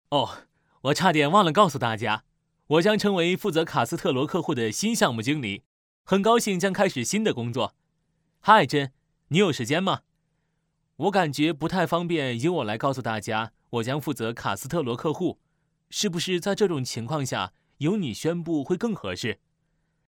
Chinese voice over